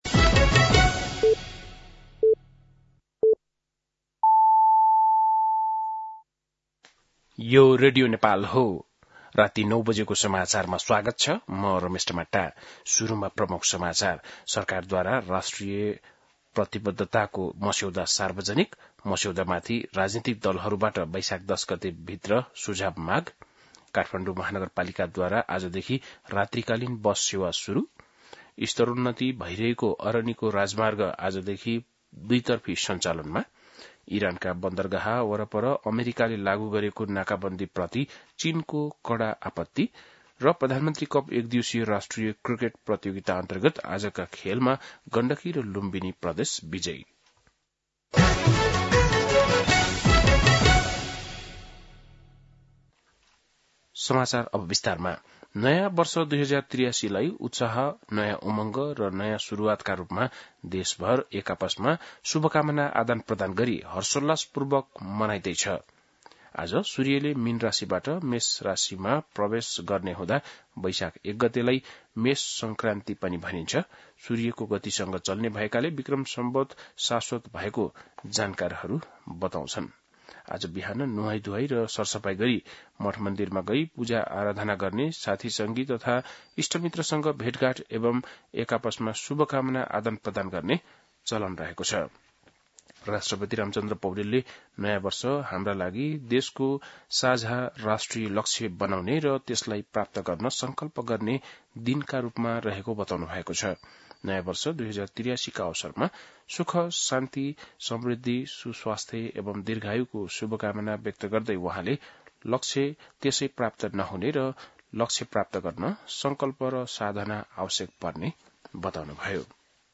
बेलुकी ९ बजेको नेपाली समाचार : १ वैशाख , २०८३
9-pm-news-1-01.mp3